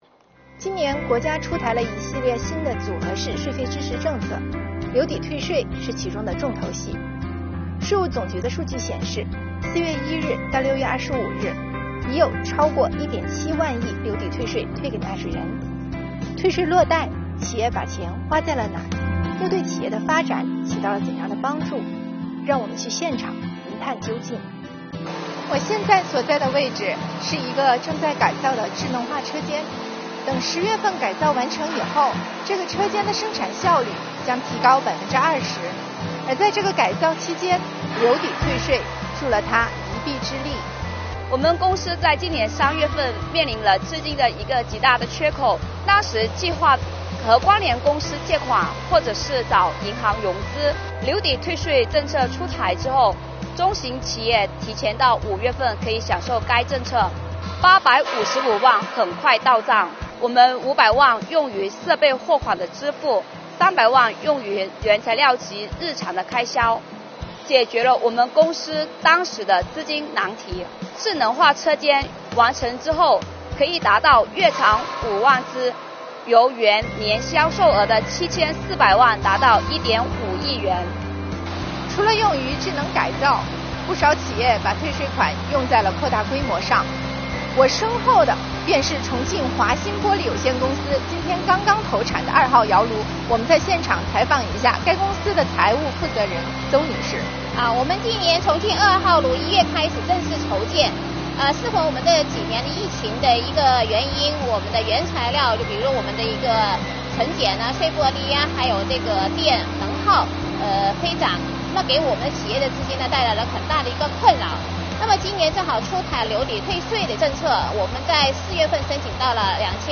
跟着记者到现场揭秘吧~